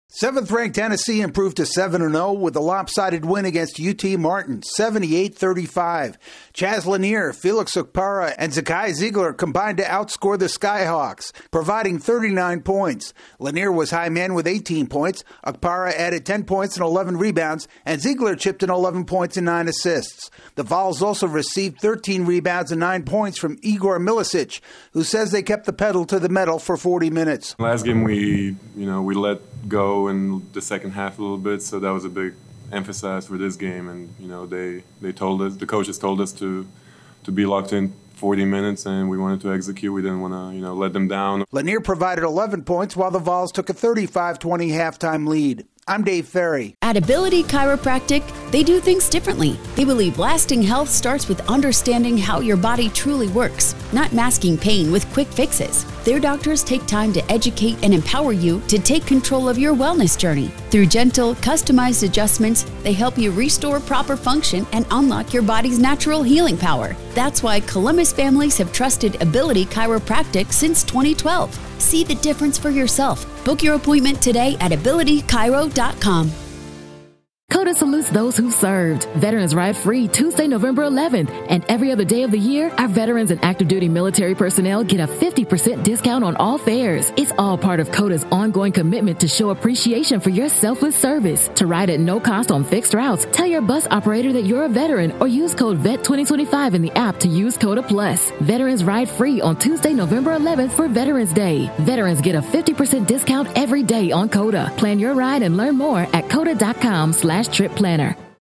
Tennessee remains unbeaten after clobbering a local foe. AP correspondent